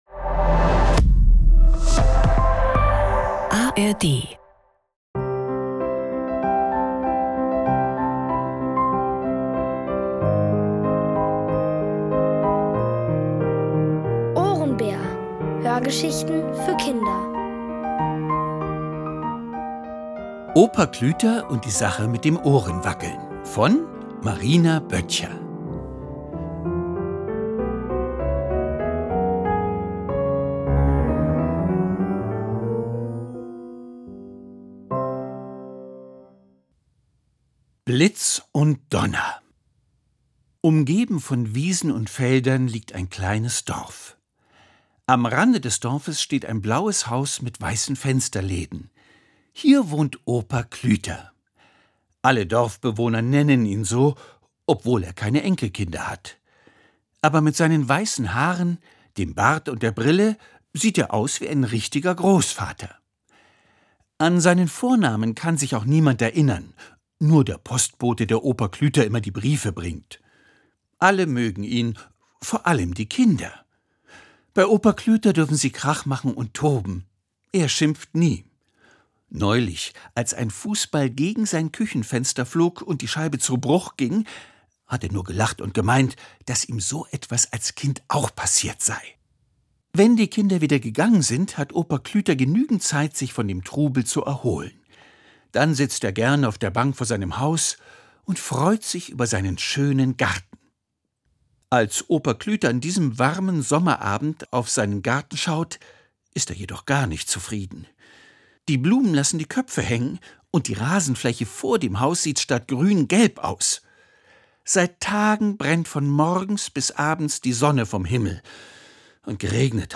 Opa Klüter und die Sache mit dem Ohrenwackeln | Die komplette Hörgeschichte!